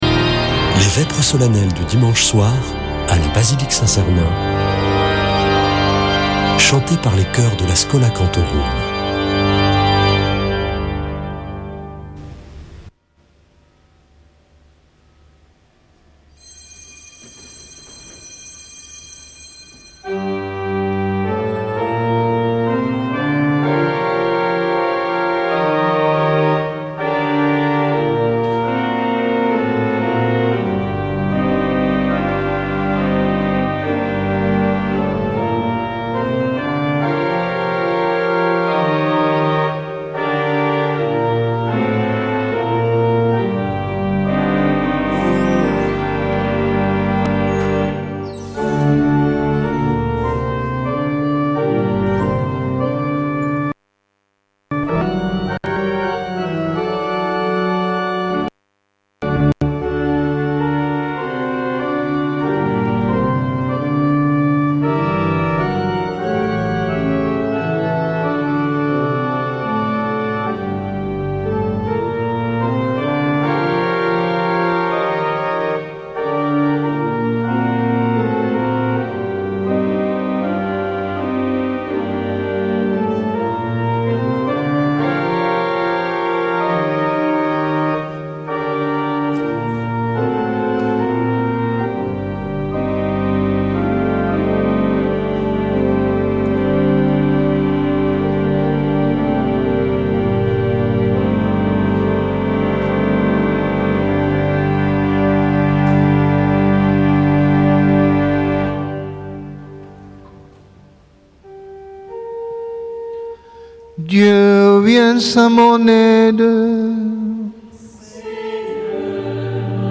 Vêpres de Saint Sernin du 16 nov.